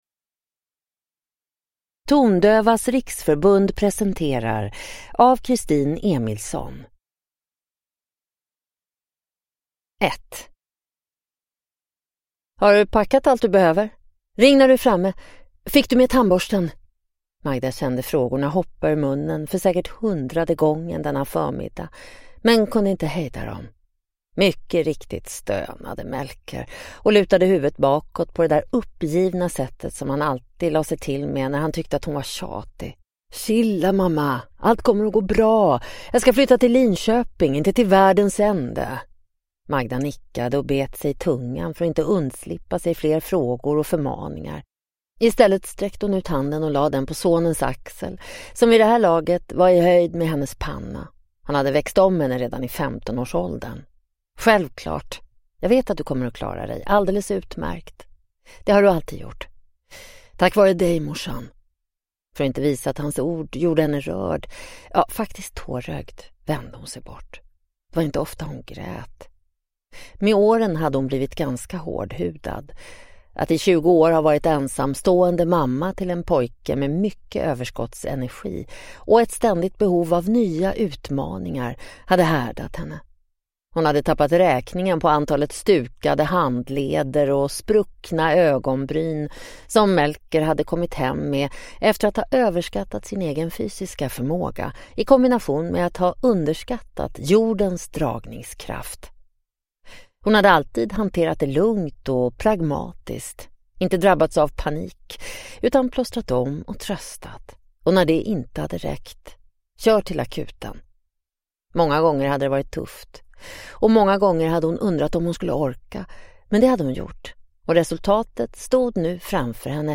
Tondövas riksförbund presenterar (ljudbok